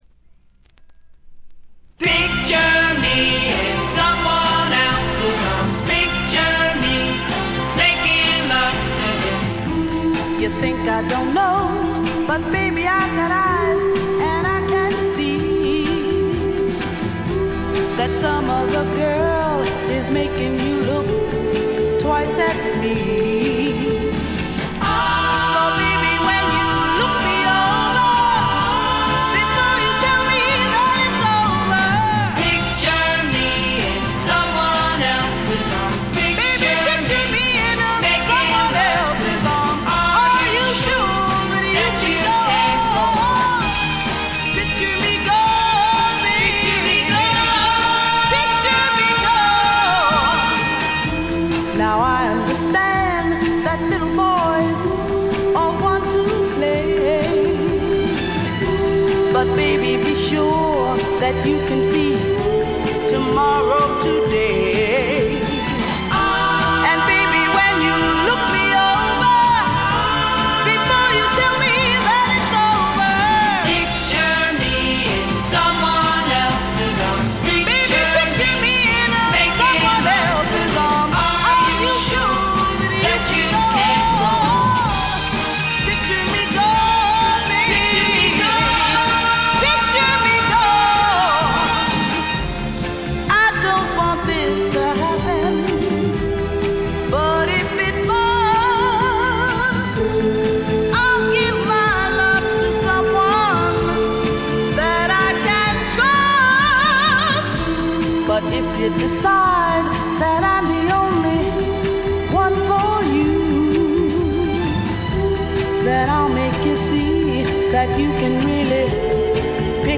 軽微なチリプチ少々。
試聴曲は現品からの取り込み音源です。